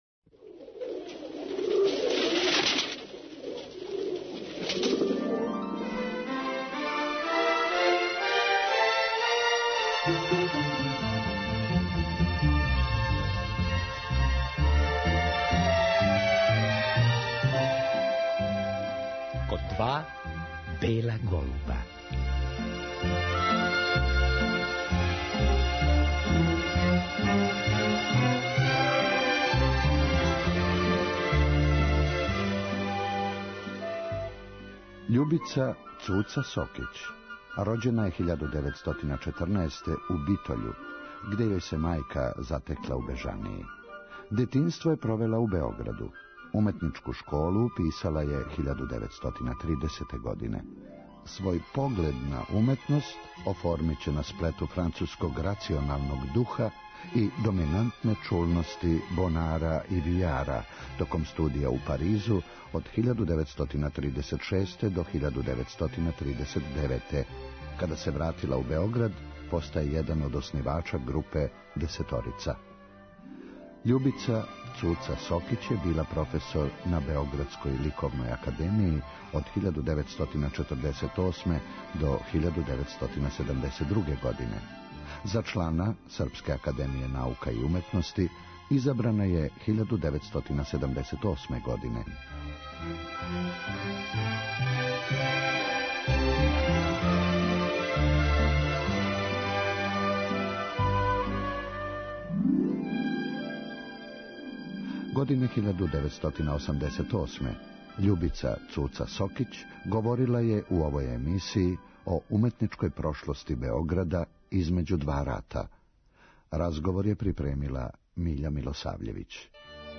То је прилика да чујемо емисију посвећену њој. У разговору